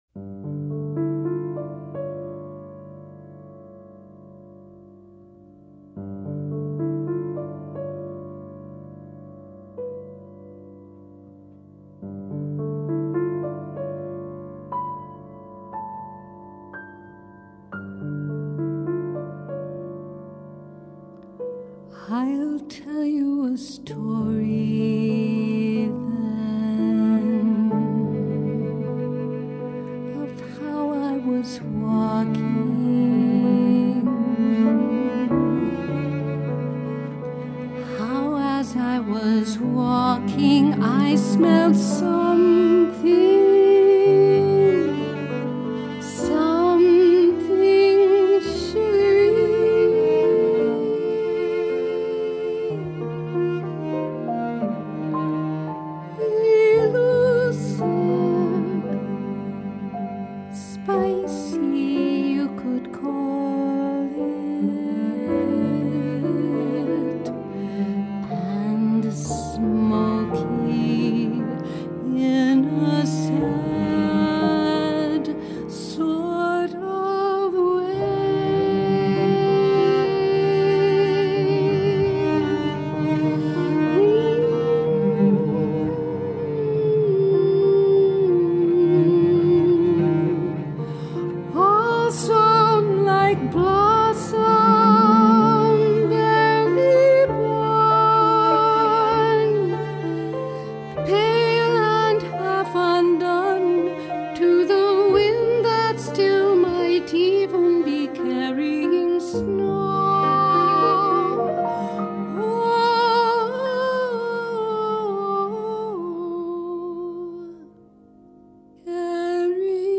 Low voice, piano